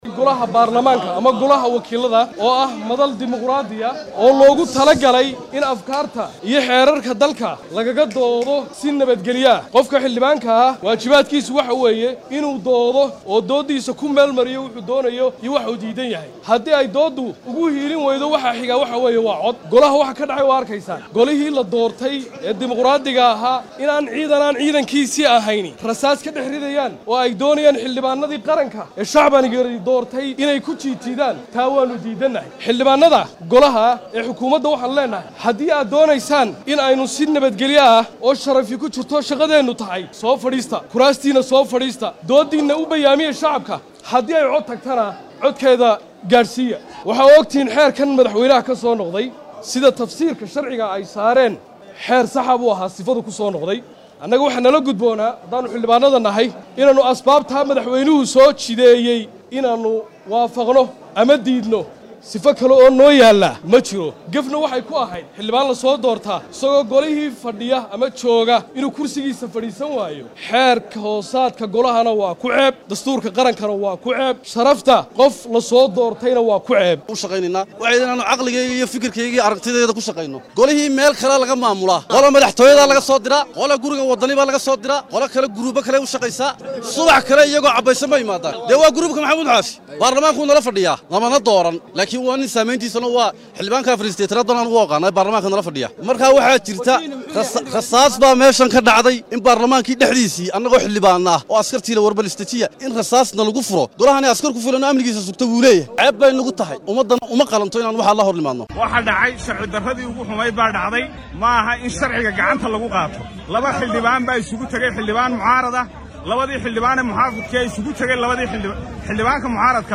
DHAGEYSO:Dagaal gacan ka hadal ah oo ka dhacay golaha wakiillada Somaliland iyo ciidamo boolis ah oo rasaas ridaaya
Waxaa wali cirka isku sii shareeraya Buuqa iyo sawaxanka Xarunta Golaha Wakiillada Somaliland, iyadoo Gudaha xarunta laga maqlay Rasaas, taasi oo buuq iyo jahwareer hor leh dhalisay. Qaar ka mid ah Xildhibaanada ayaa bannaanka hore ee xarunta Warbaahinta kula hadlaya, waxaana Xildhibaanadu ay u kala qayb sameen dhowr qaybood oo iska soo hor jeeda.